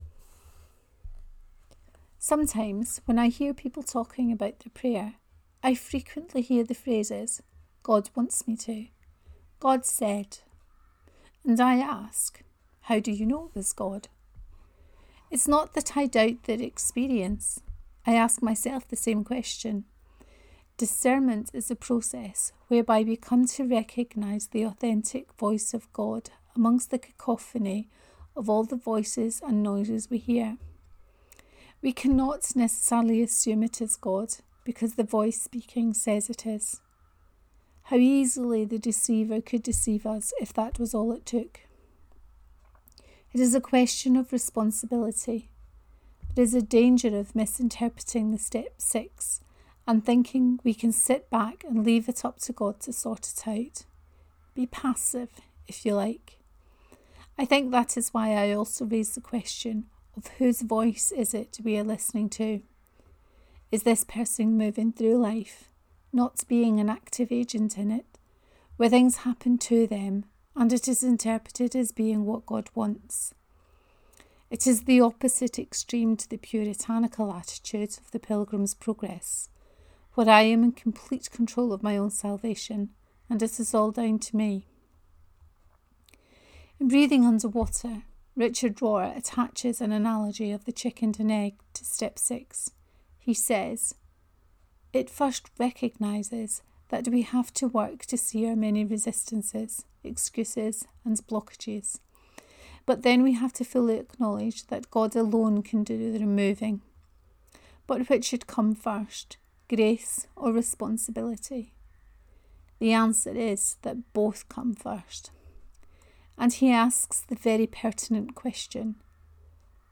Were entirely ready…2a: Reading of this post